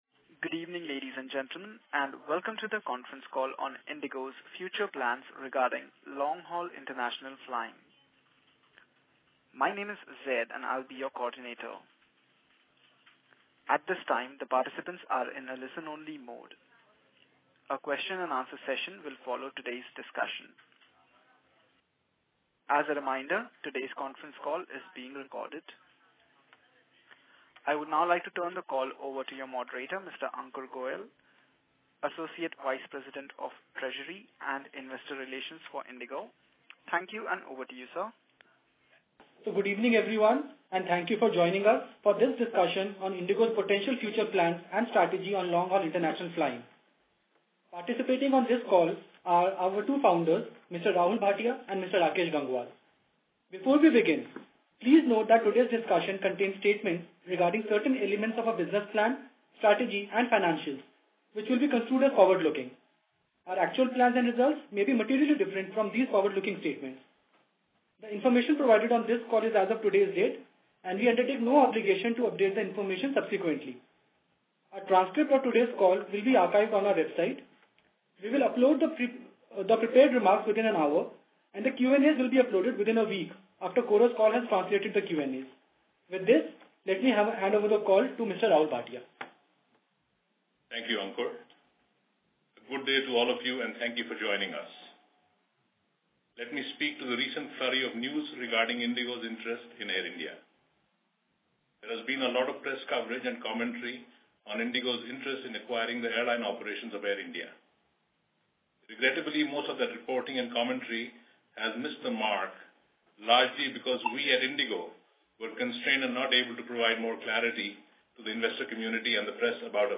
Conference-Call-Recording.mp3